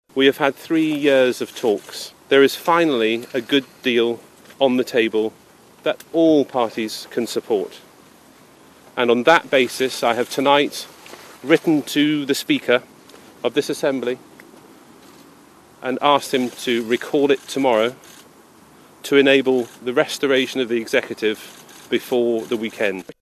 Speaking last night, Northern Secretary Julian Smith urged all of the North’s parties to back the deal: